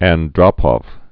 (ăn-drŏpôf), Yuri 1914-1984.